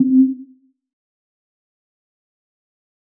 Boups/Musics/sound effect/hower.wav at 5e5b2d93e770967b2918dd12f3e0fcaa0aa54522